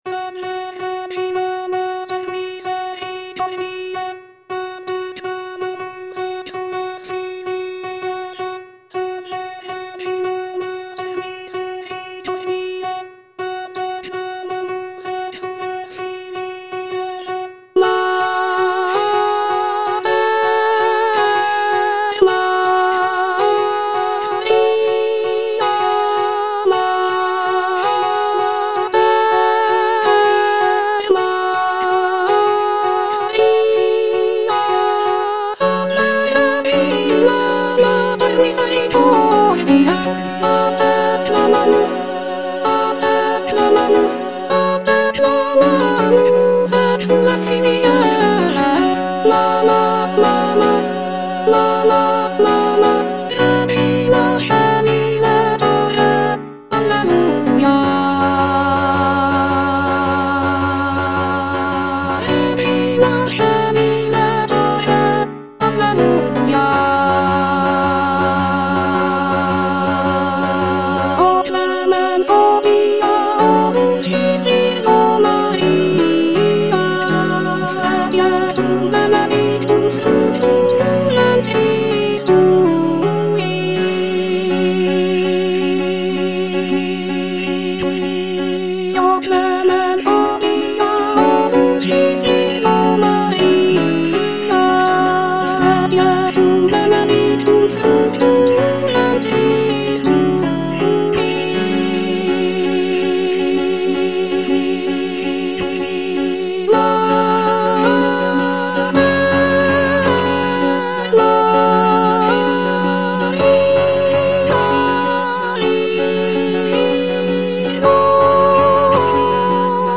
Soprano II (Emphasised voice and other voices) Ads stop